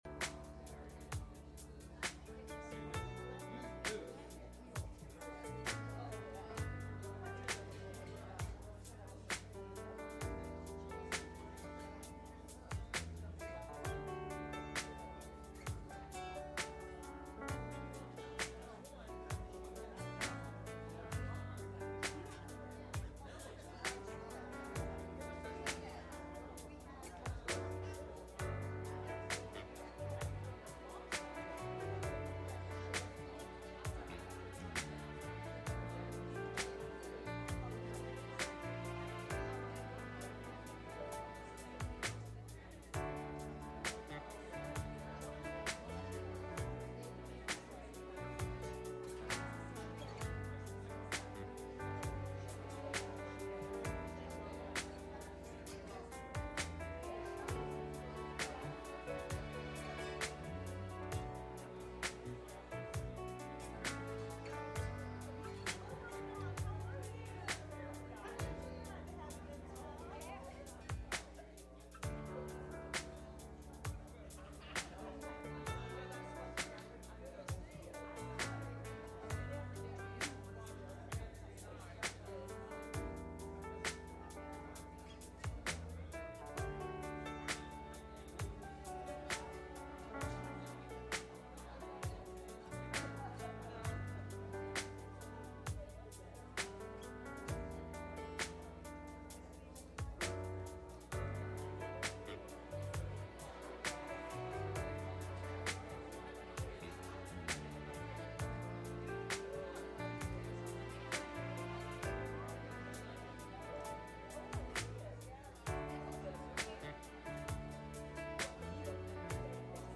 Sunday Morning Service
Service Type: Sunday Morning